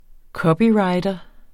Udtale [ ˈkʌbiˌɹɑjdʌ ]